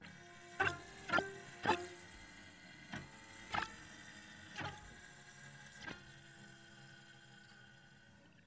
cello-phrase_hprModel_residual
analysis cello harmonic model phrase sines sinusoidal stochastic sound effect free sound royalty free Voices